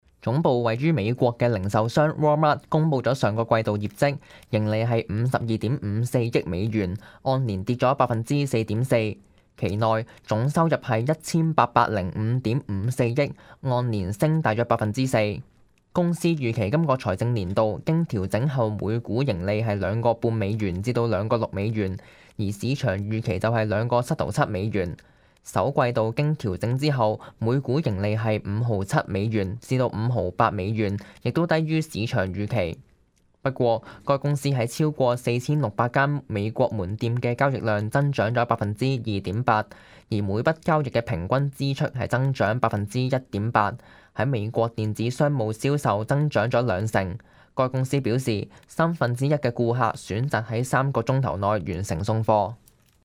news_clip_22553.mp3